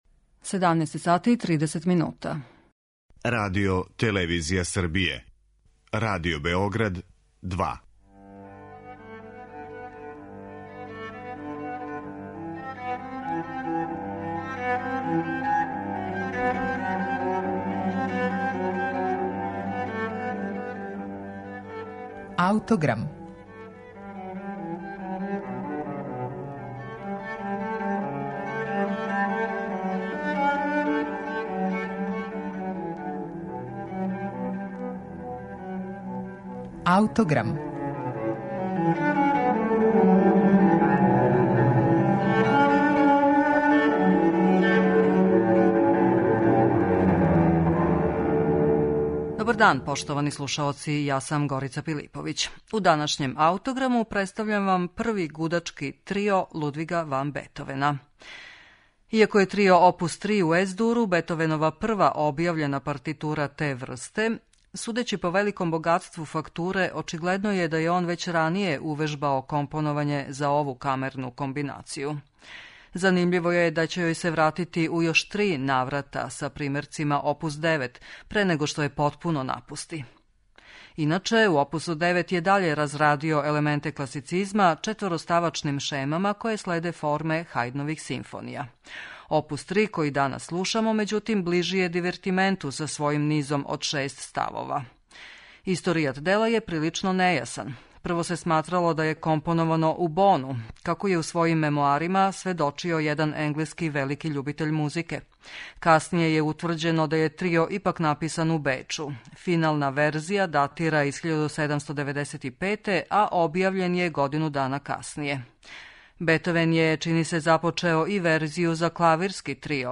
БЕТОВЕНОВ ПРВИ ГУДАЧКИ ТРИО
Опус 3, који данас слушамо, међутим, ближи је дивертименту са својим низом од шест ставова.